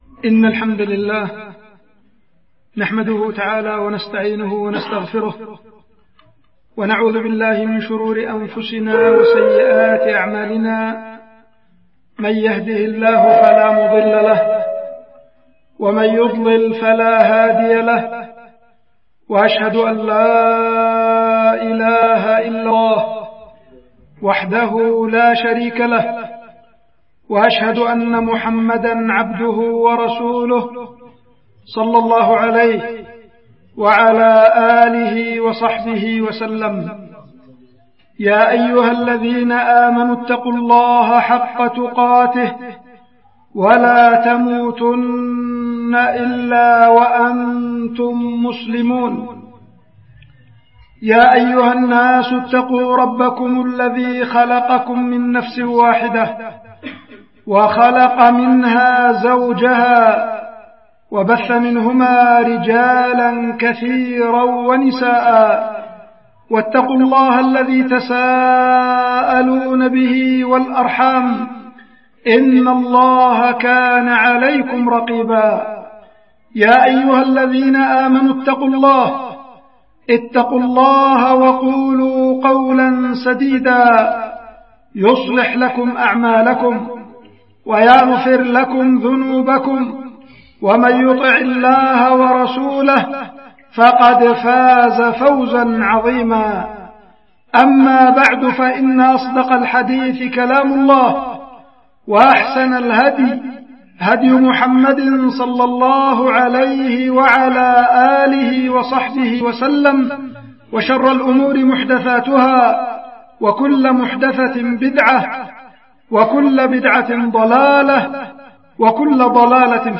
خطبة
القيت في مسجد الطاعة حي السبل مدينة إب